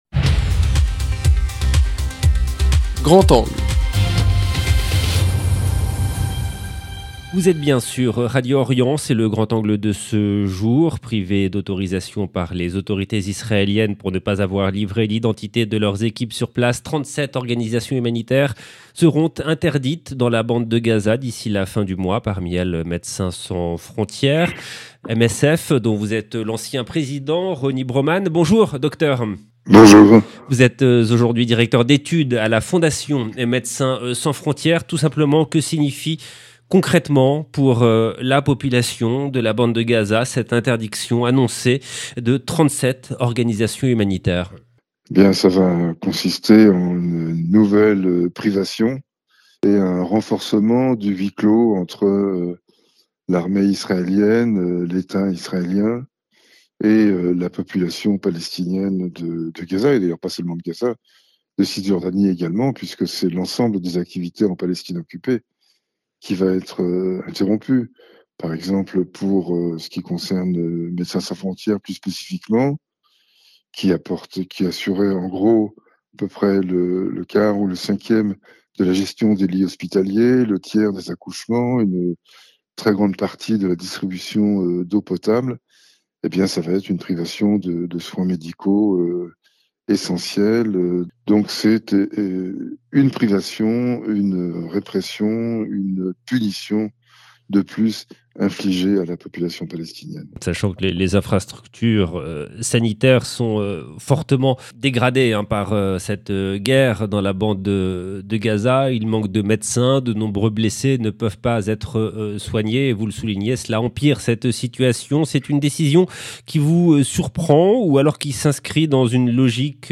Eclairage avec RONY BRAUMAN, Directeur d'études à la Fondation Médecins Sans Frontières, ancien président de MSF.